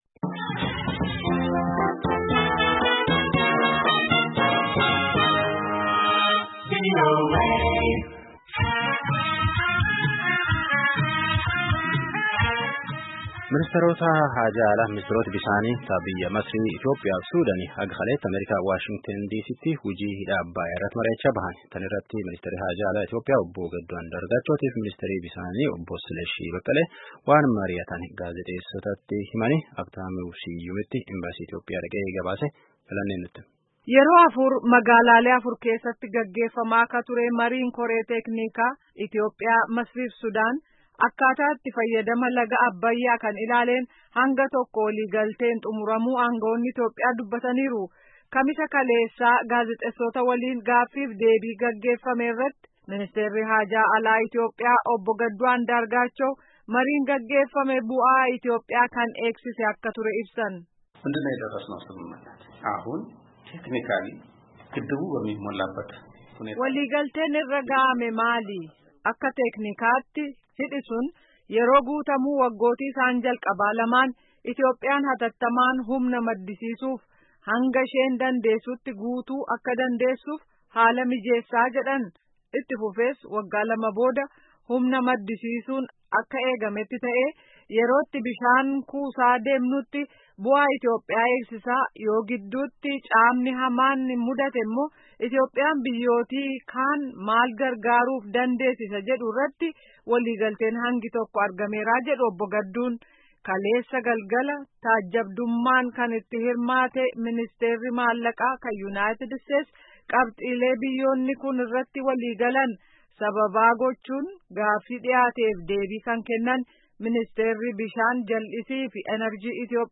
Jilli Itiyoophiyaa kun embaasii Itiyoophiyaa waashington keessa jiruutti Kamiisa Amajjii 16 bara 2020 tuuta oduuf ibsa kennee ture caqasaa.